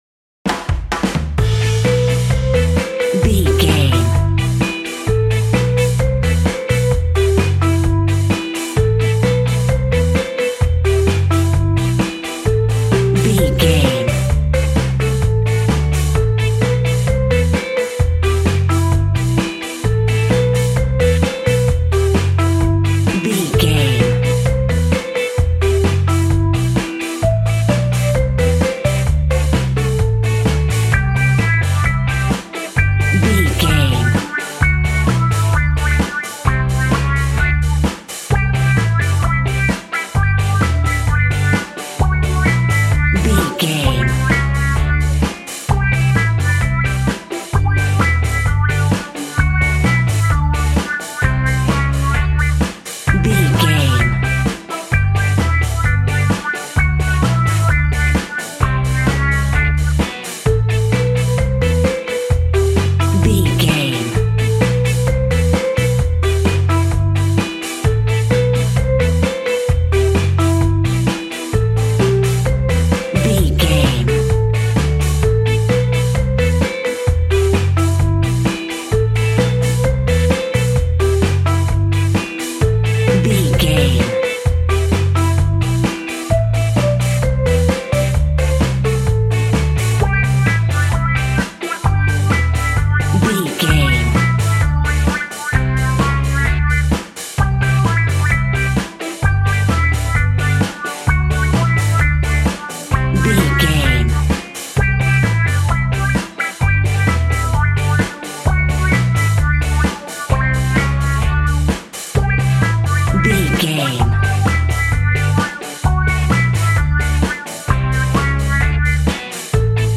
Classic reggae music with that skank bounce reggae feeling.
Aeolian/Minor
reggae
dub
laid back
chilled
off beat
drums
skank guitar
hammond organ
percussion
horns